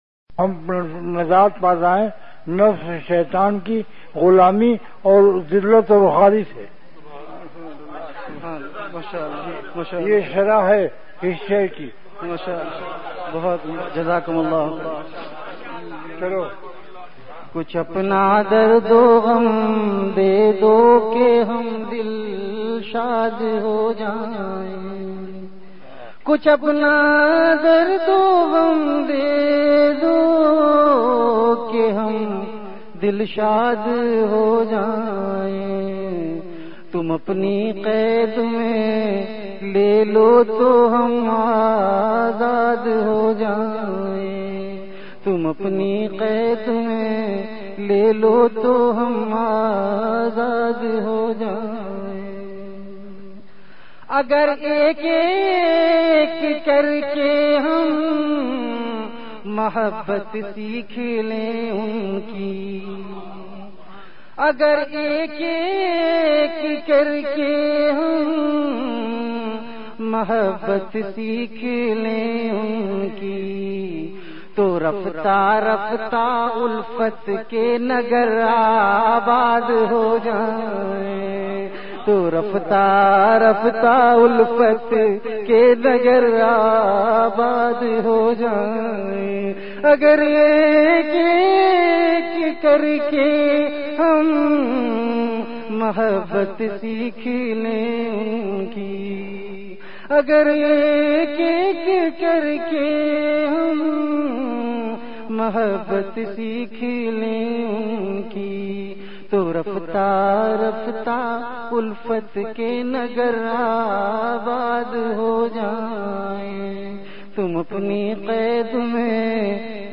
Delivered at Khanqah Imdadia Ashrafia.
Ashaar · Khanqah Imdadia Ashrafia